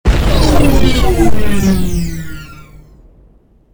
railgunlaunch.wav